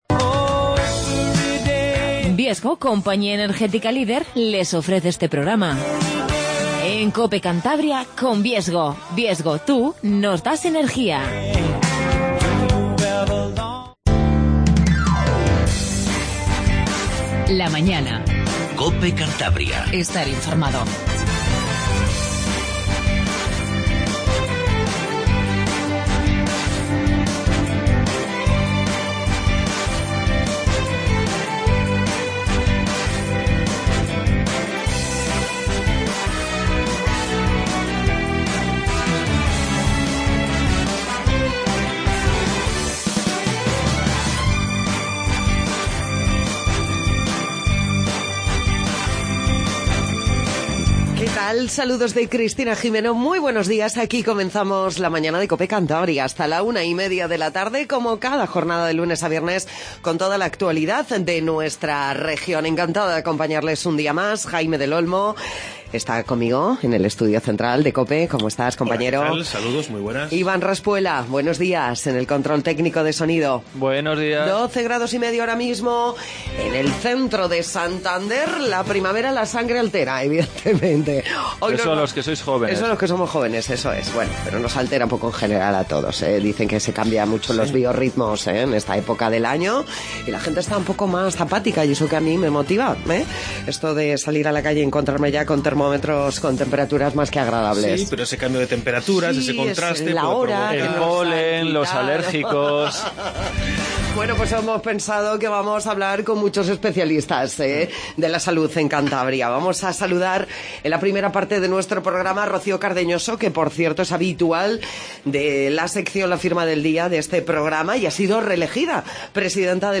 Magazine